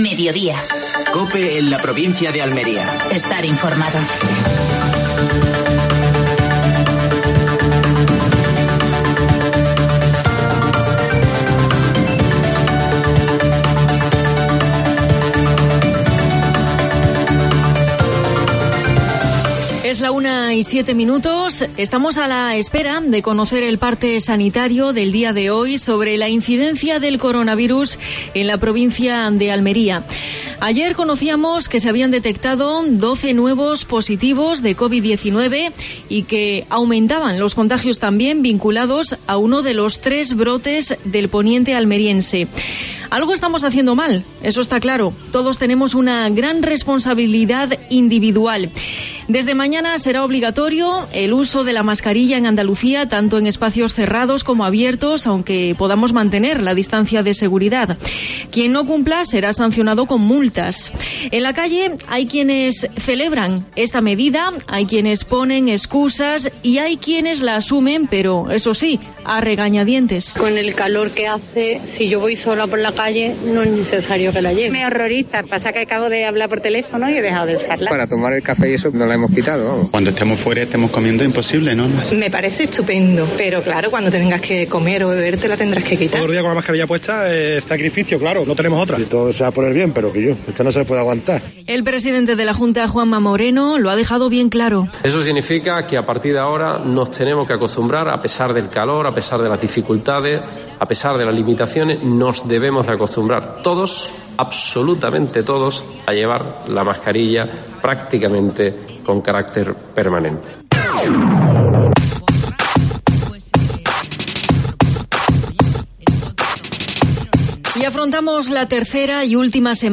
AUDIO: Actualidad en Almería. Entrevista a Jesús Caicedo (presidente Autoridad Portuaria de Almería).